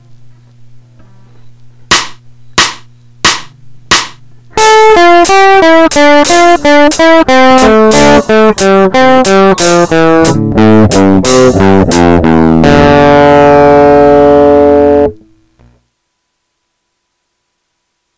音程が下降していくたびに小指が先行していくので大変です。